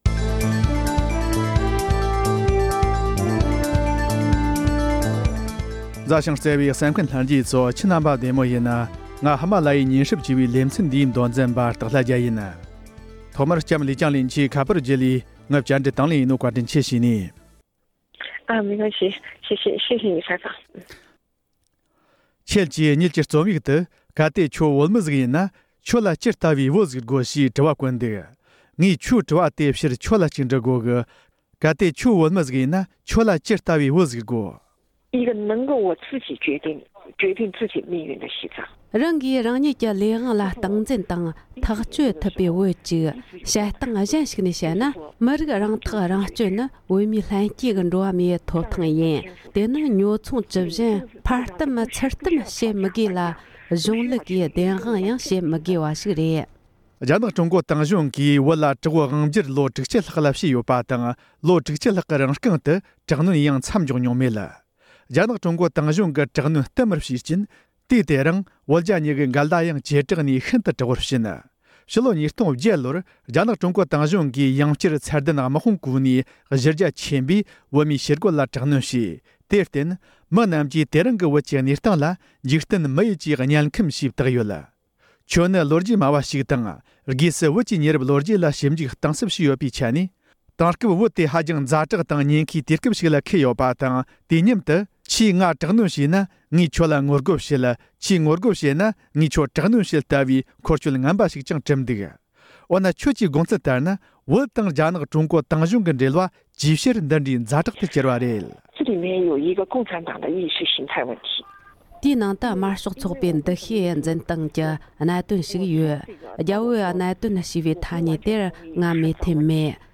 བོད་དོན་གྱི་མདུན་ལྗོངས་དང་བོད་དོན་ཐག་གཅོད་བྱ་རྒྱུར་རྒྱ་ནག་དམངས་གཙོ་ཅན་དུ་འགྱུར་རྒྱུར་འབྲེལ་བ་ཡོད་མེད་ཐད་བཅར་འདྲི།